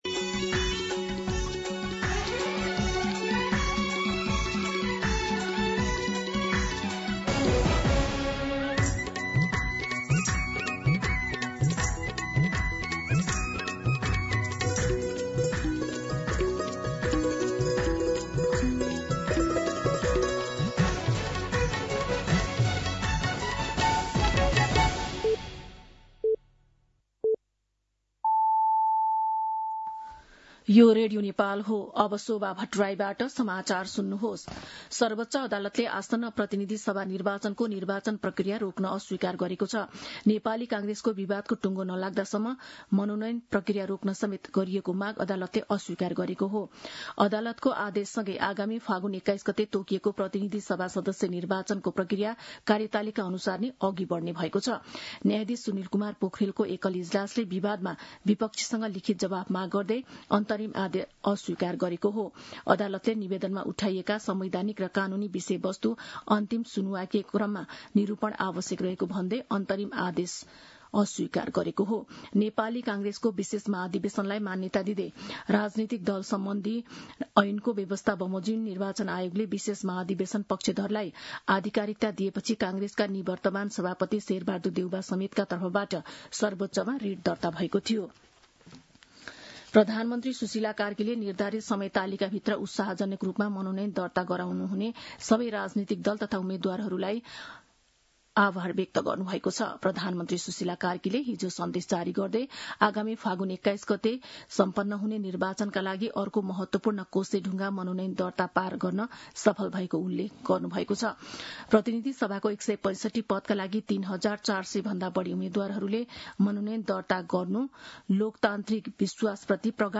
दिउँसो १ बजेको नेपाली समाचार : ७ माघ , २०८२
1pm-Nepali-News-1.mp3